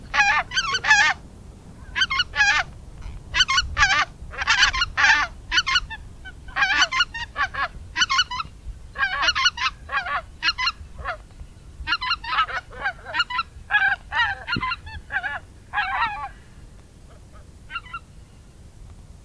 kolgans
🔭 Wetenschappelijk: Anser albifrons
♫ contactroep 2
kolgans_roep_2021.mp3